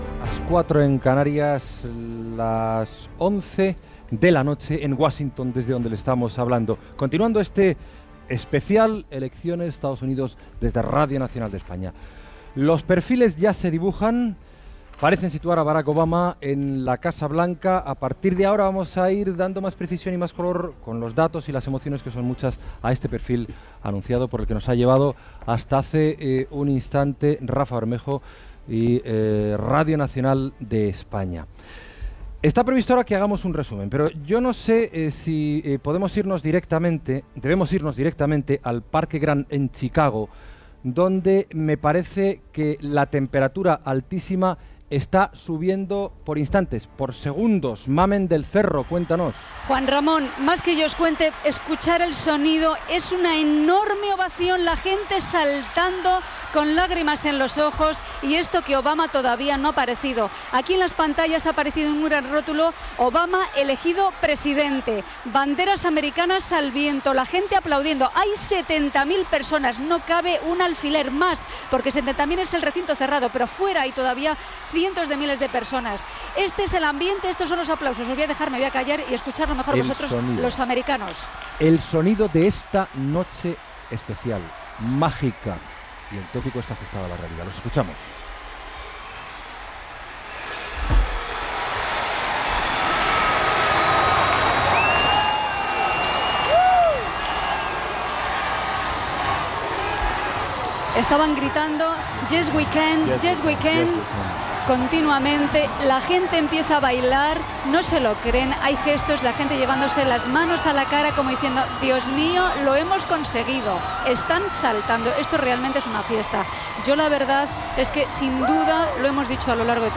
Hora, connexió amb Chicago, Barack Obama guanya les eleccions a la presidència dels EE.UU. i la CNN confirma que serà el nou president.
Discurs de Barack Obama a Chicago.
Informatiu